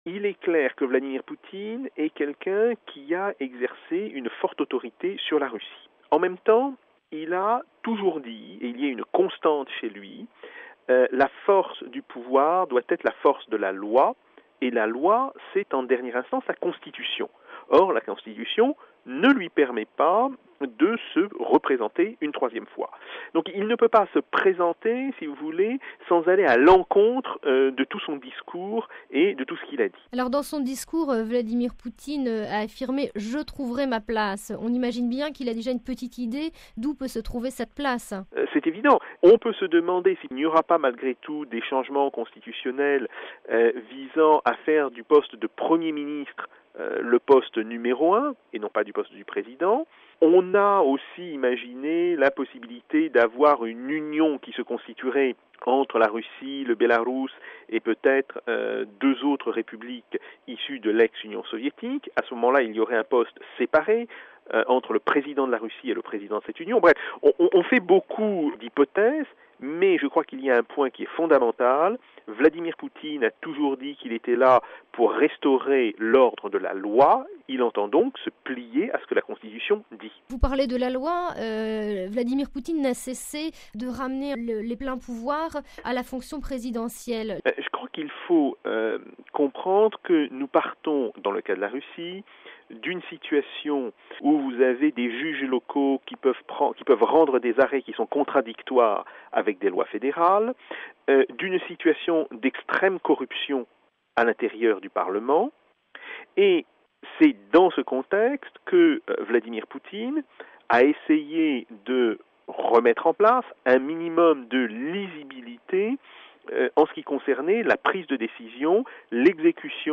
Comment donc expliquer cette décision, où en est aujourd’hui la Russie sur le plan interne et international. L’analyse de Jacques Sapir, spécialiste de la Russie à l’Ecole des Hautes Etudes en Sciences Sociales.